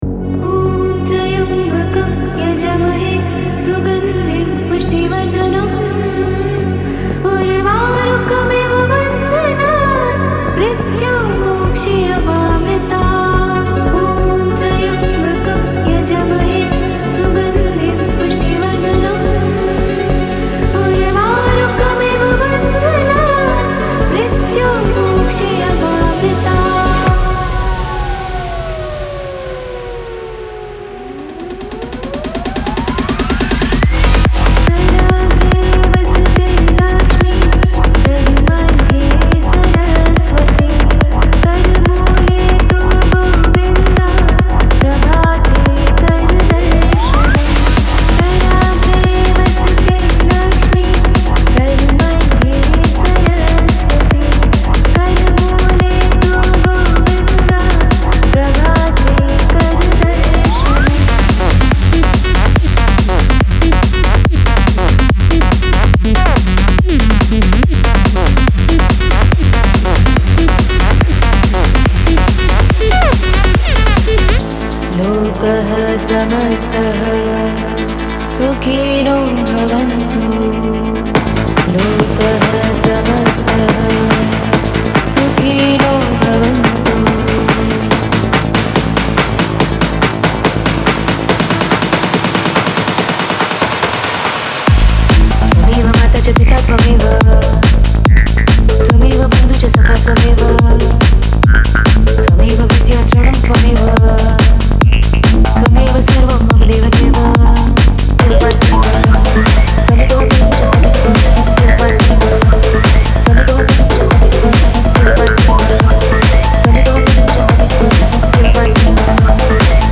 本ツールキットは、Goaを感じさせる雰囲気からフルオンのエネルギーまでを提供します。
デモサウンドはコチラ↓
Genre:Psy Trance
142 BPM